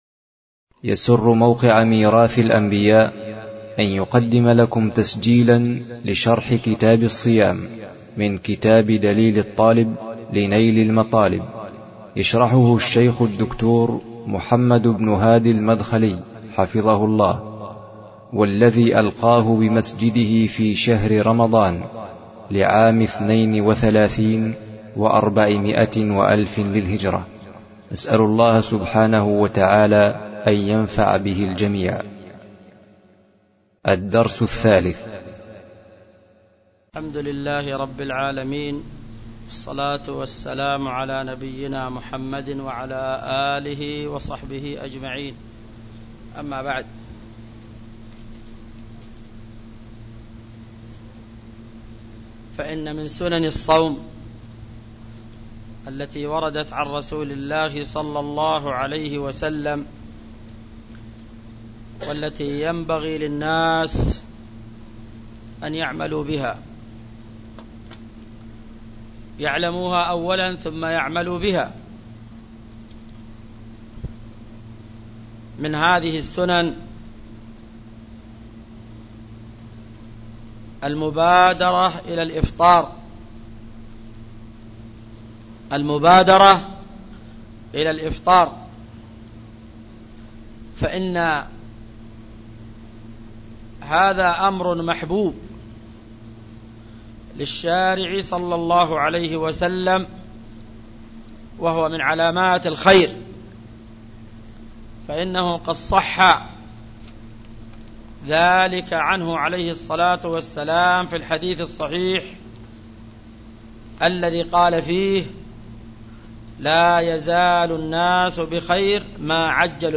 شرح دليل الطالب لنيل المطالب الدرس 3